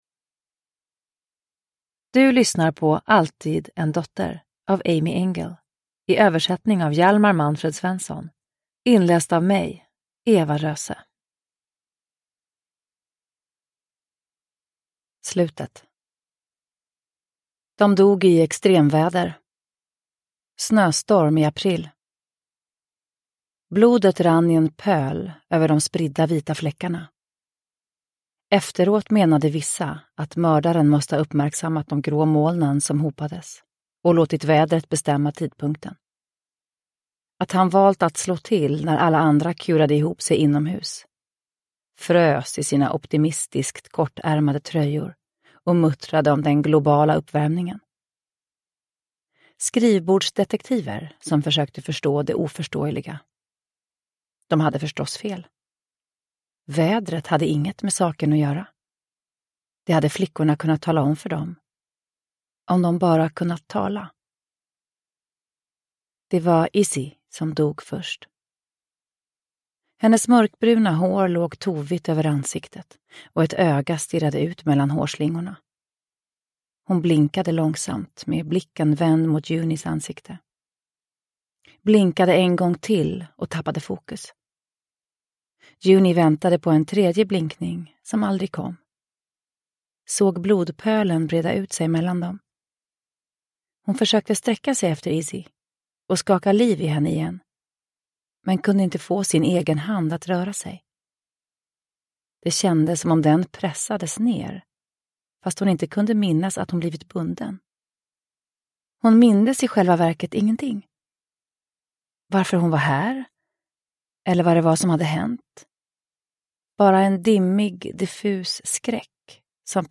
Alltid en dotter – Ljudbok – Laddas ner
Uppläsare: Eva Röse